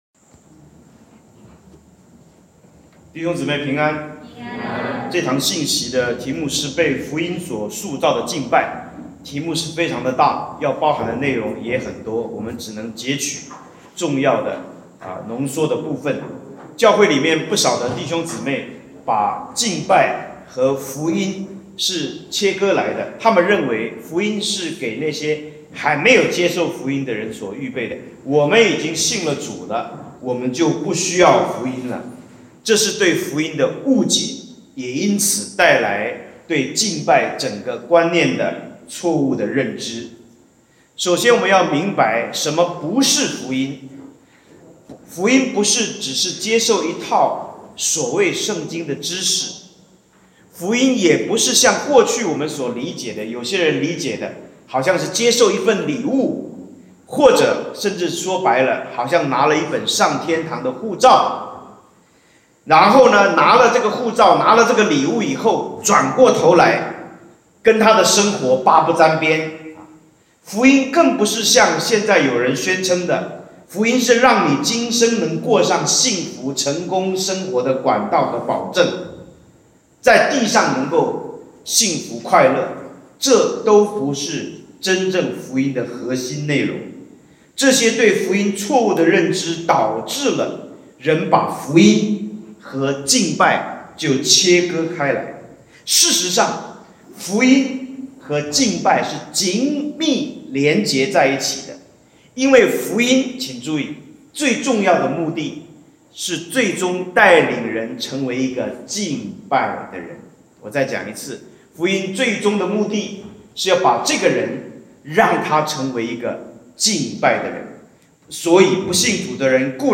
錄音）雙福2021退修會：專題四-【-被福音塑造的敬拜-】.mp3